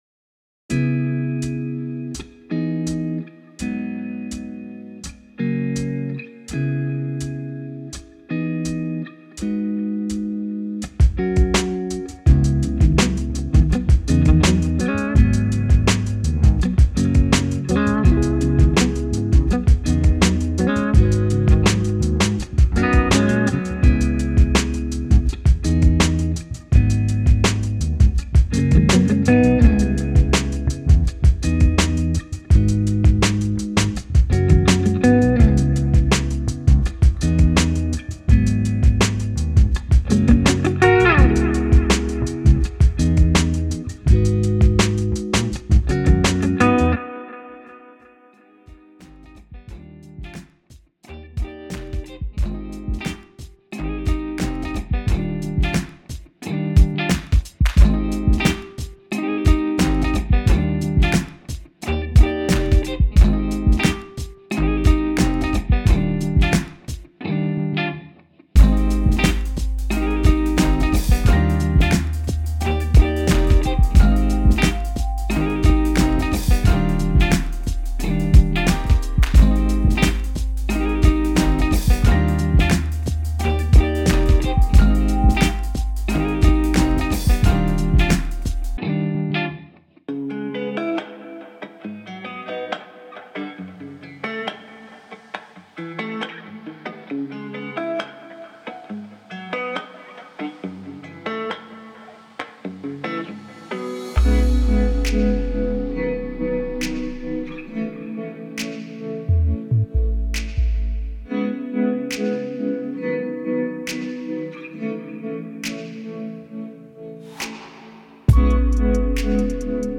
音色试听
吉他采样包